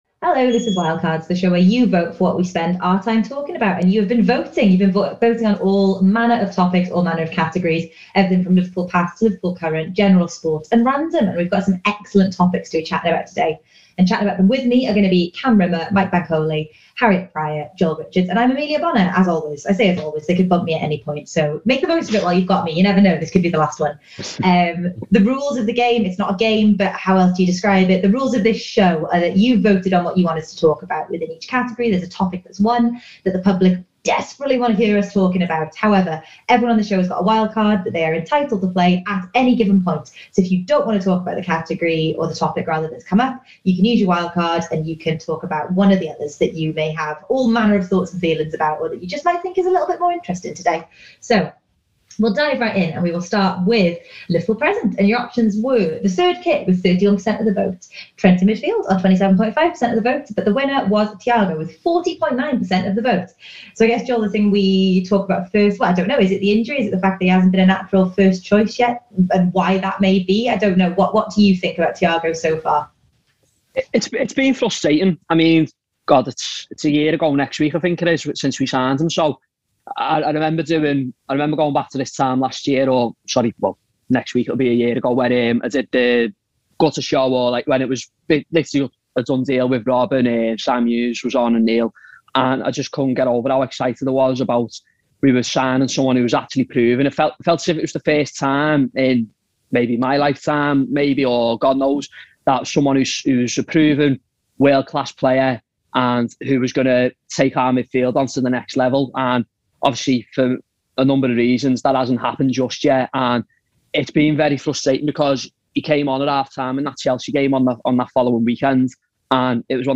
The panel discuss the latest on Thiago Alcantara, the greatness of Robbie Fowler, the biennial World Cup idea, Emma Raducanu and The Wire.